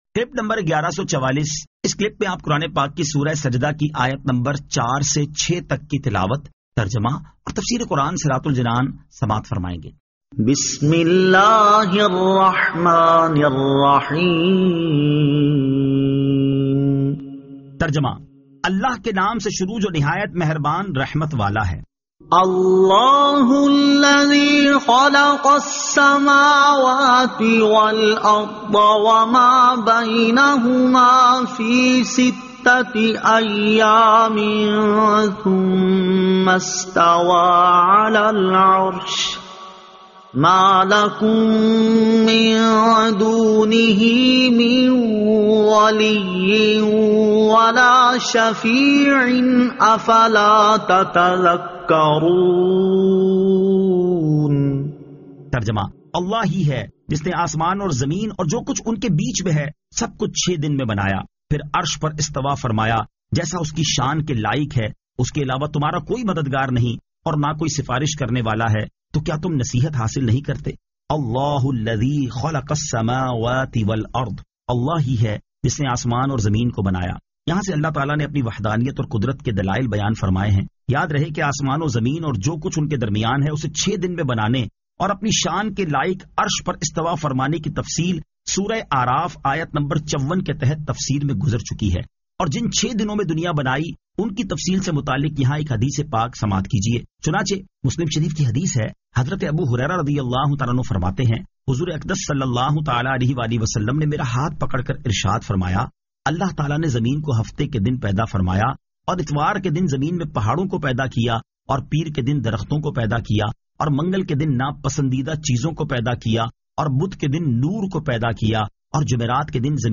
Surah As-Sajda 04 To 06 Tilawat , Tarjama , Tafseer